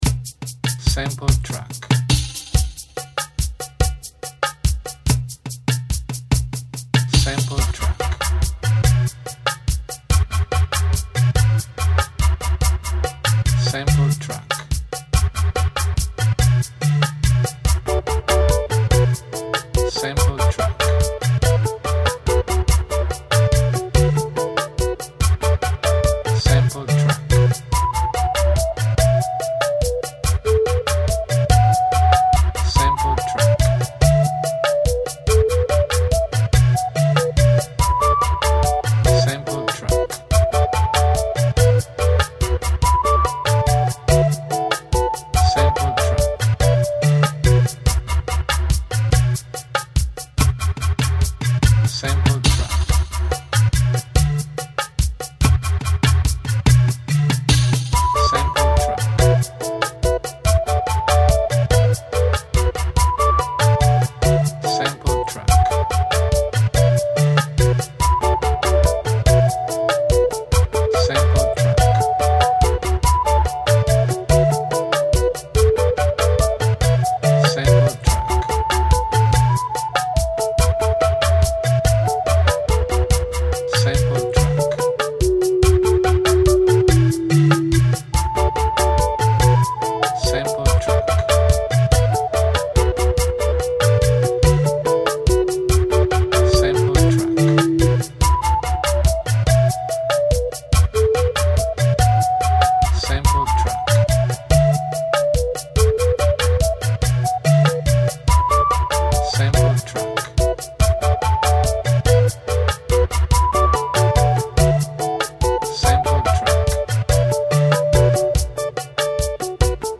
AFRICA Ritmo africano in 6/8, strumenti etnici.